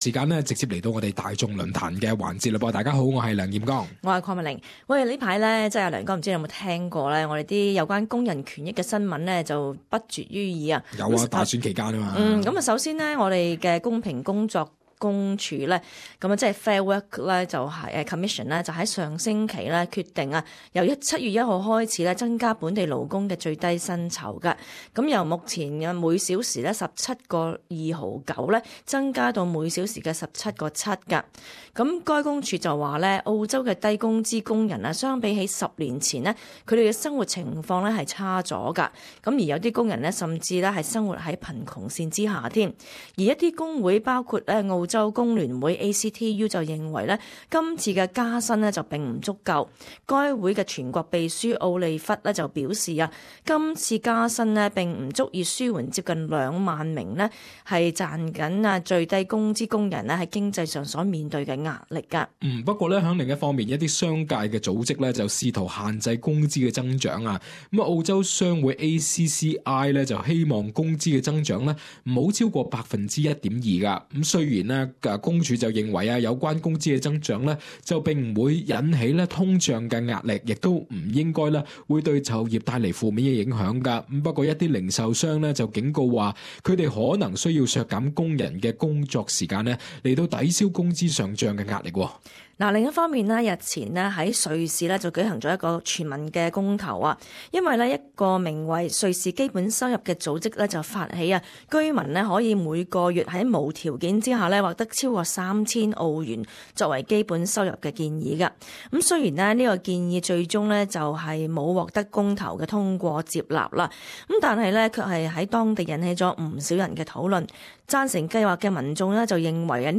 在今天的『大众论坛』， 我们与听众讨论： 当你在澳洲寻找工作时，你有否遇到过一些不公平对待呢？你又是否觉得澳洲目前所订的最低工资，根本未能为工人提供合理的生活水平呢？